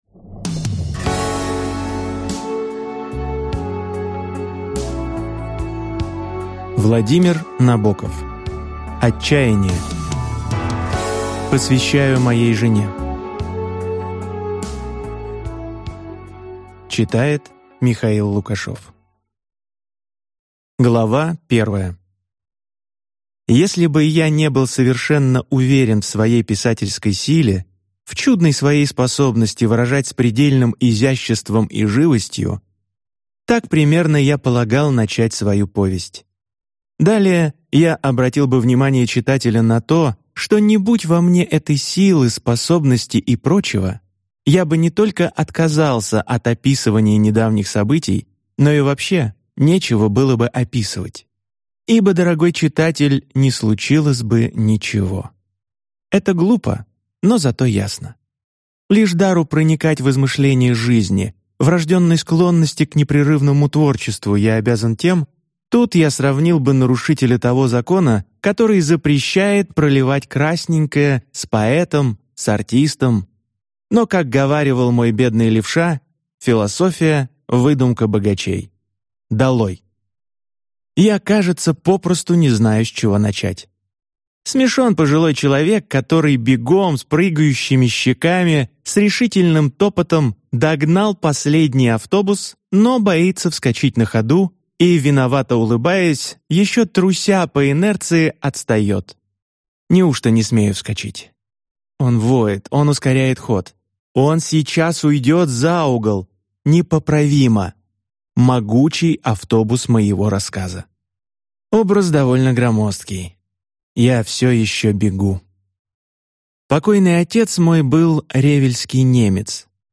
Аудиокнига Отчаяние | Библиотека аудиокниг